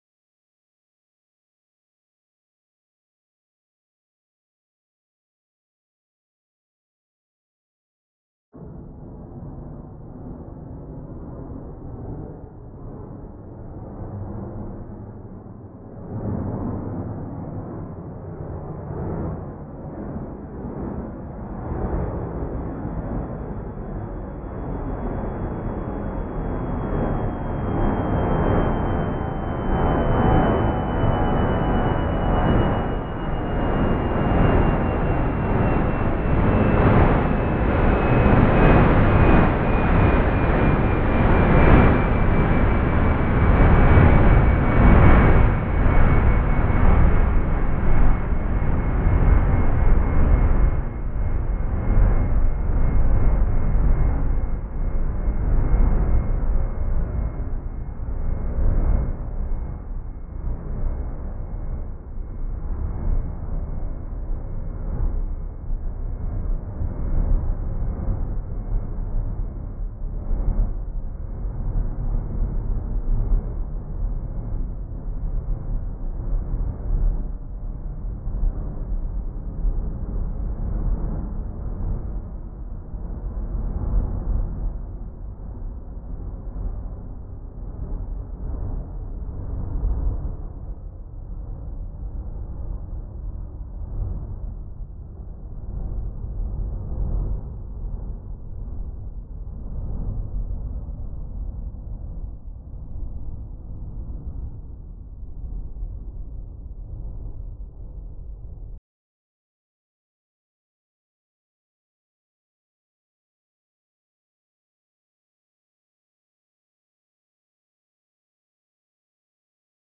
Auralization of atmospheric turbulence-induced amplitude fluctuations in aircraft flyover sound based on a semi-empirical model | Acta Acustica
04_synthesis_high_turbulence.mp3 (9